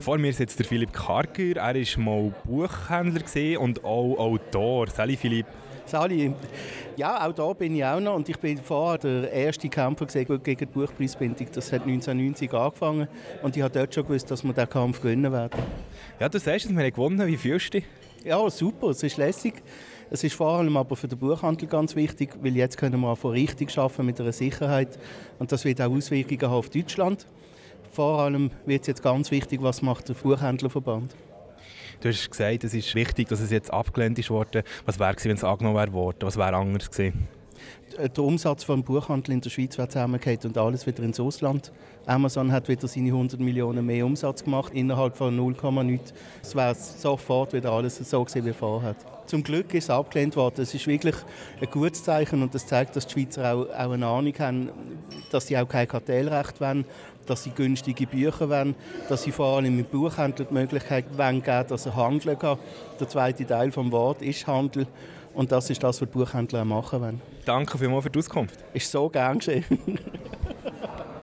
Abstimmungsparty